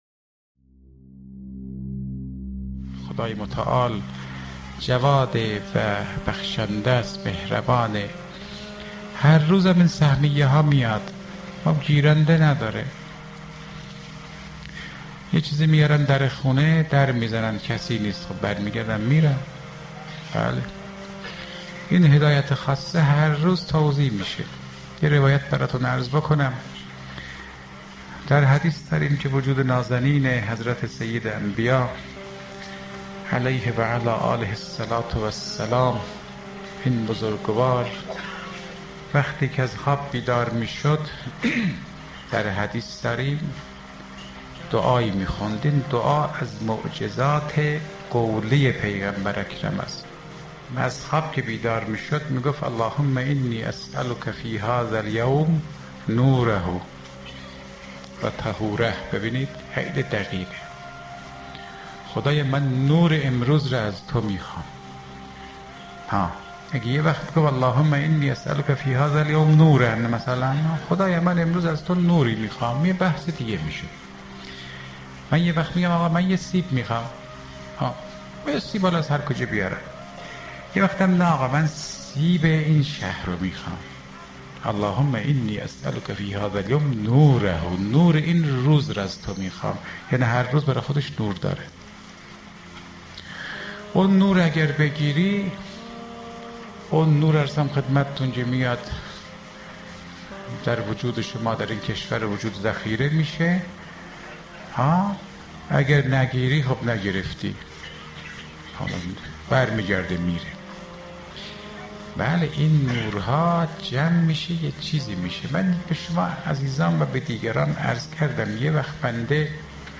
دانلود حفظ انوار الهی کسب شده سخنران : آیت الله فاطمی نیاء حجم فایل : - مگابایت زمان : 0 دقیقه توضیحات : موضوعات : دسته بندی ها آیت الله فاطمی نیاء اخلاق